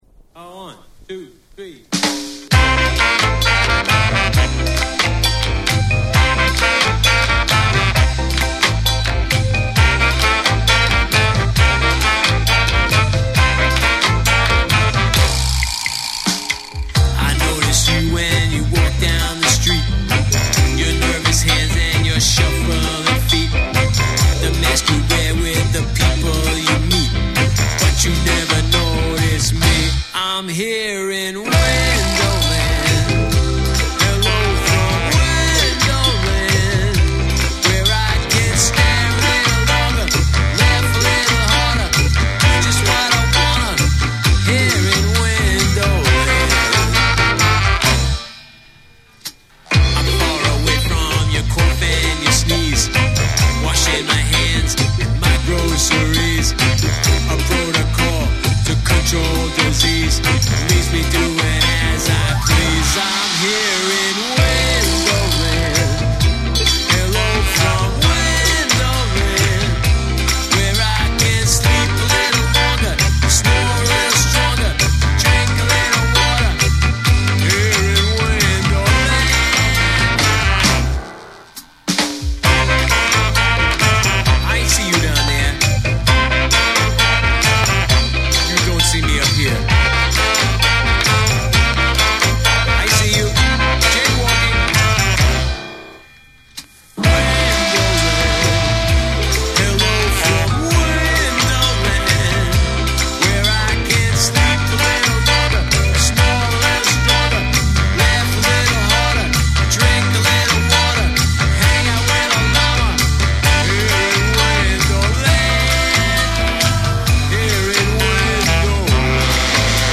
オールディーズ〜ロックな雰囲気も漂わせるスカ・ナンバーを収録。
REGGAE & DUB